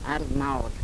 armâr, pr. armáor,